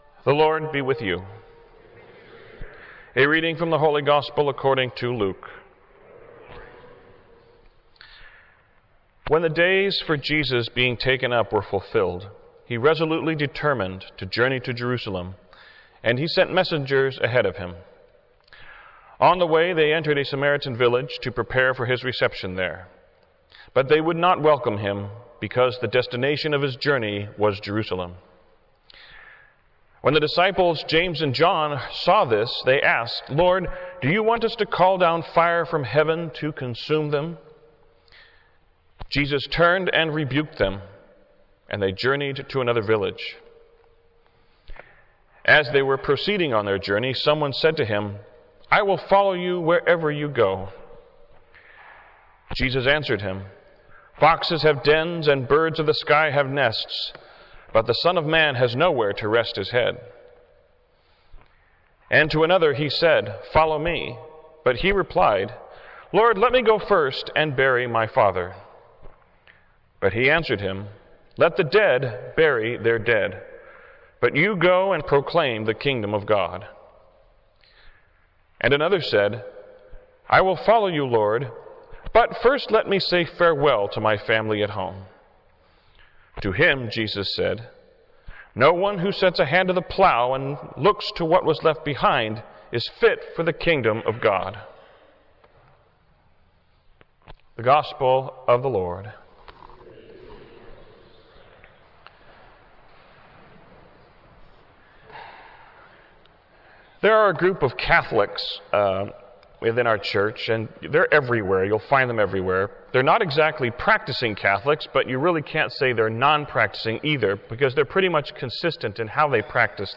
Homily (with Gospel Reading) for the 13th Sunday of Ordinary Time
Homily-13thSundayCYes-But2.wav